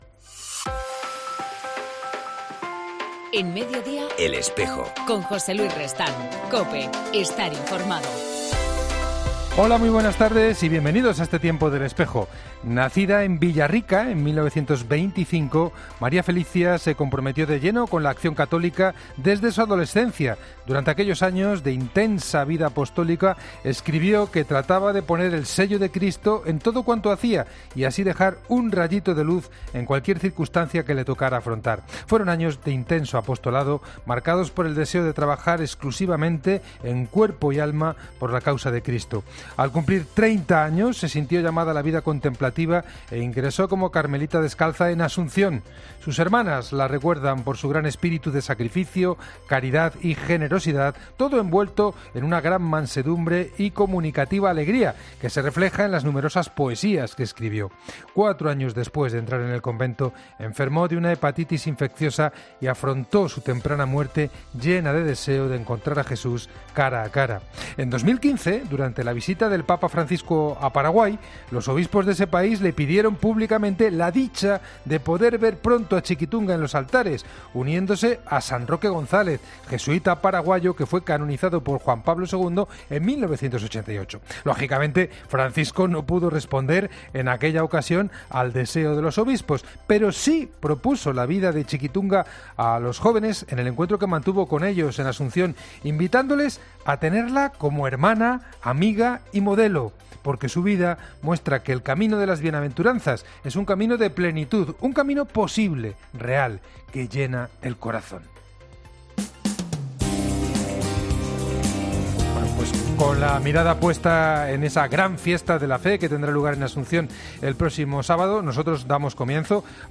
En El Espejo del 19 de junio entrevistamos al obispo de la Diócesis de Sigüenza-Guadalajara, Atilano Rodríguez
El Espejo En El Espejo del 19 de junio entrevistamos al obispo de la Diócesis de Sigüenza-Guadalajara, Atilano Rodríguez En El Espejo nos vamos a Sigüenza, porque esta tarde se abre la puerta santa para el Año Jubilar con motivo del 850 aniversario de su catedral. Para saber los detalles de esta celebración hoy entrevistamos al obispo de Sigüenza-Guadalajara, Mons. Atilano Rodríguez.